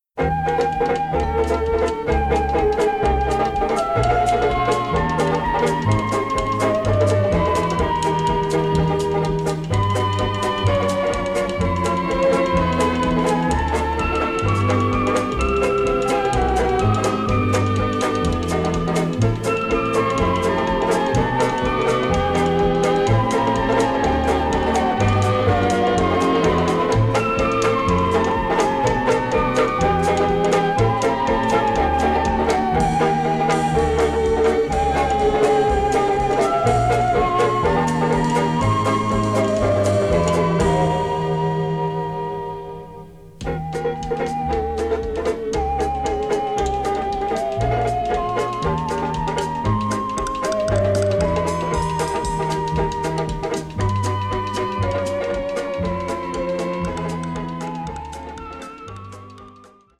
including the tango and Charleston